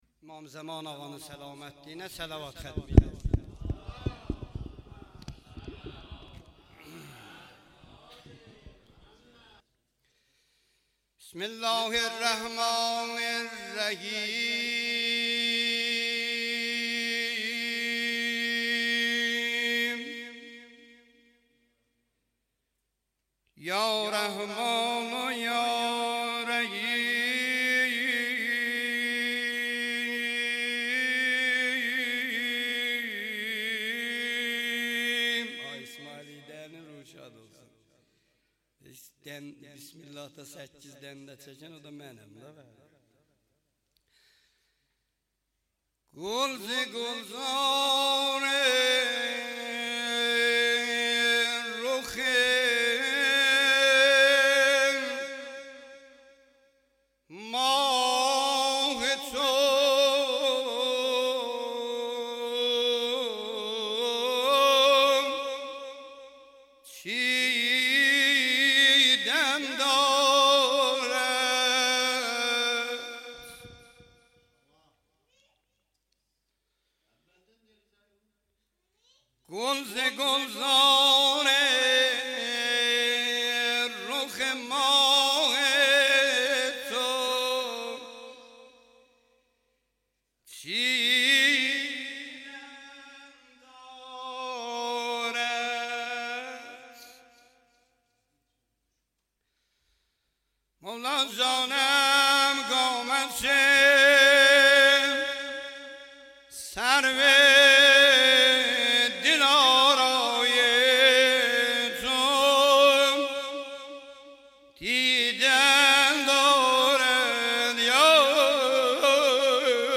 جلسه هفتگی